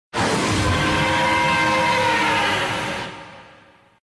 Gatanothor_Roars.ogg